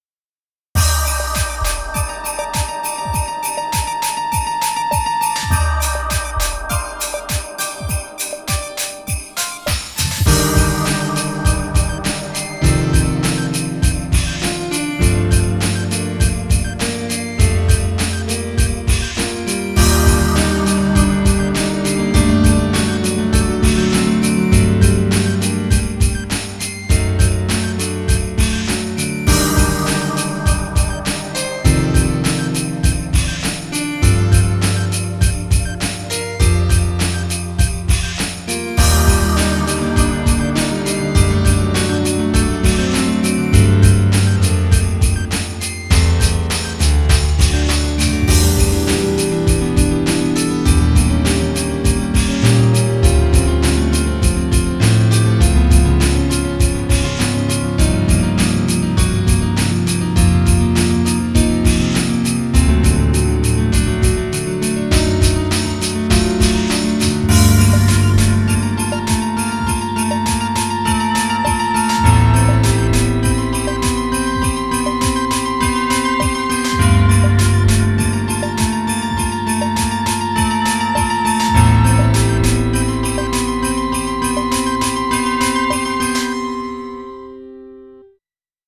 BPM101